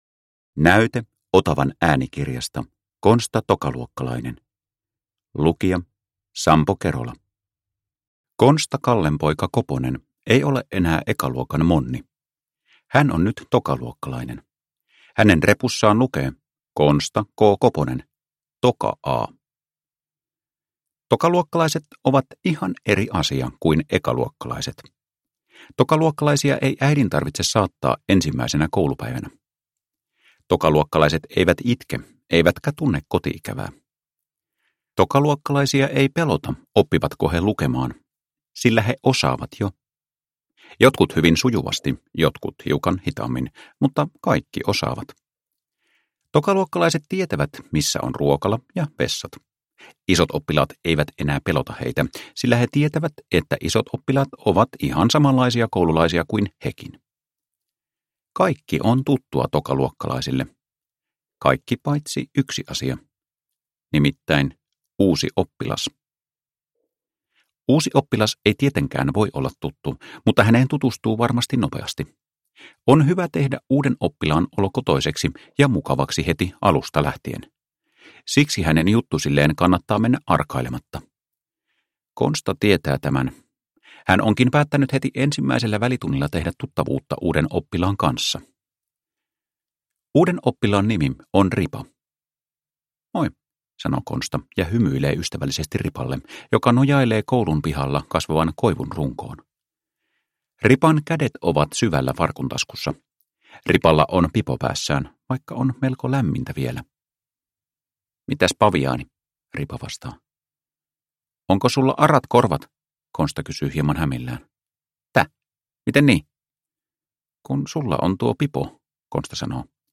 Konsta, tokaluokkalainen – Ljudbok – Laddas ner